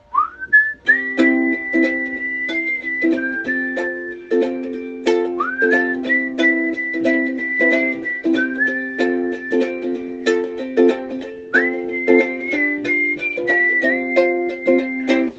Bassriff-Quiz
Und mich jetzt leider auch, daher möchte ich Euch teilhaben lassen an diesem Ohrwurm Ich habe da ganz starke 80er-Assoziationen, komme aber nicht weiter… your_browser_is_not_able_to_play_this_audio